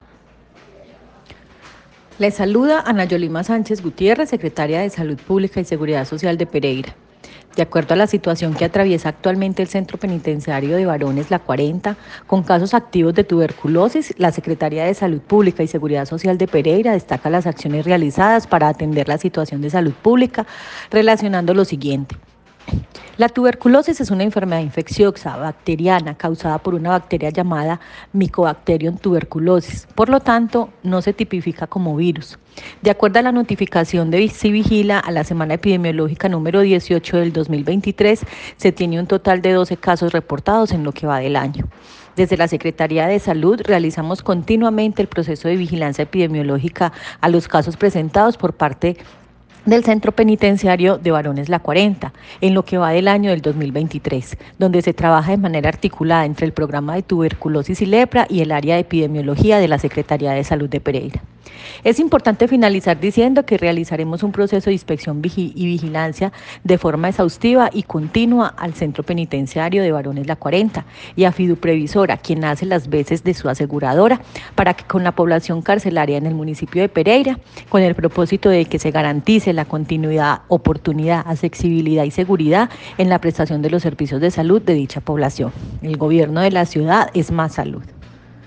La secretaria de Salud Pública y Seguridad Social de Pereira, Ana Yolima Sánchez Gutiérrez, destacó que al interior del penal se han realizado acciones inmediatas para atender la situación de salud pública y evitar que el brote siga aumentando.
Secretaria_Salud_Yolima_Sanchez_.mp3